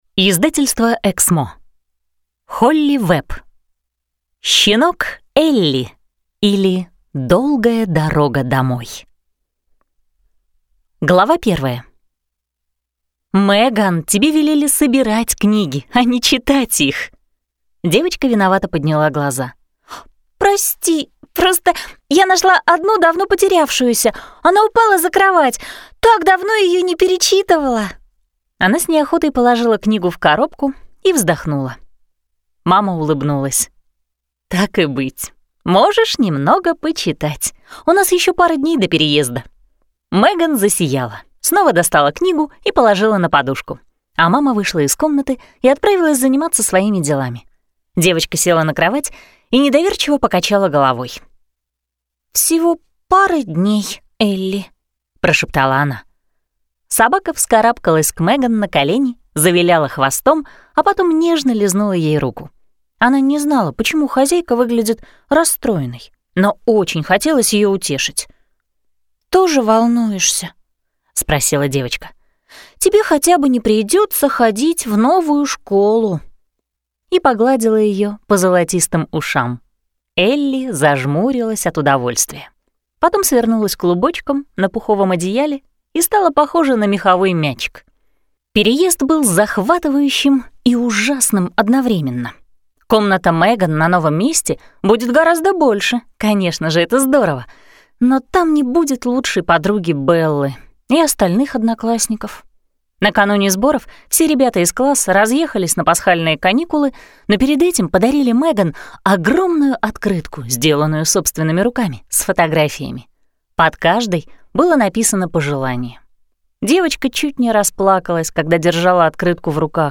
Аудиокнига Щенок Элли, или Долгая дорога домой | Библиотека аудиокниг
Прослушать и бесплатно скачать фрагмент аудиокниги